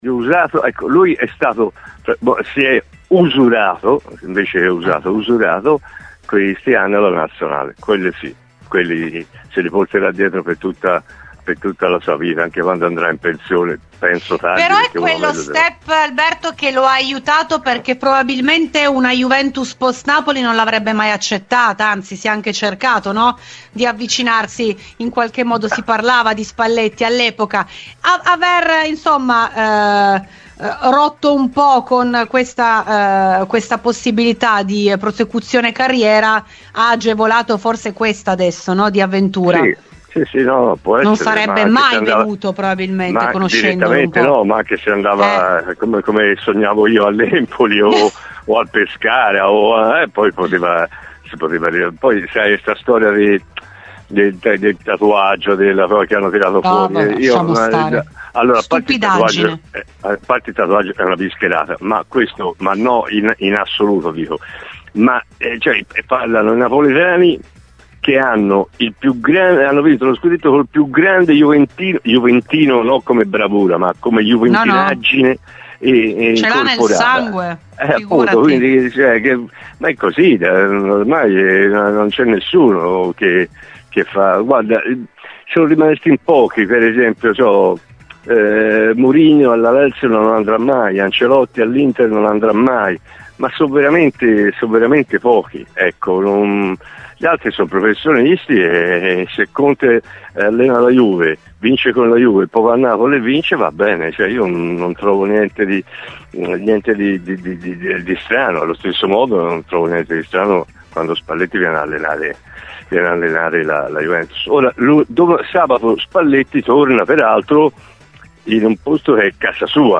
Spalletti da una parte, Vanoli dall'altra, moduli diversi a confronto, allenatori con un'esperienza ancor più diversa ma decisi a cambiare il corso di una stagione iniziata sotto le aspettative. Ne abbiamo parlato con l'attuale allenatore del Ravenna, ai microfoni di RBN Cafè su Radiobianconera.